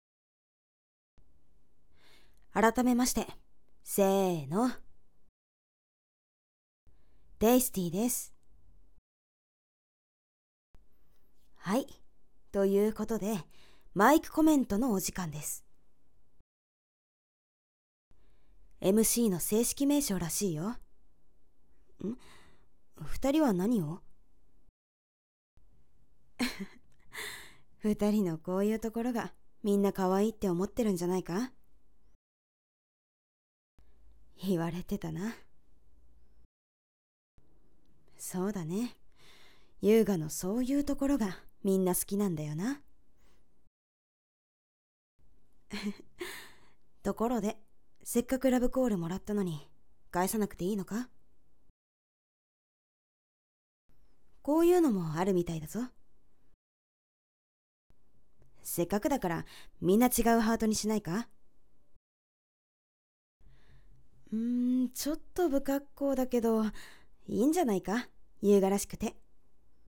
💍 声劇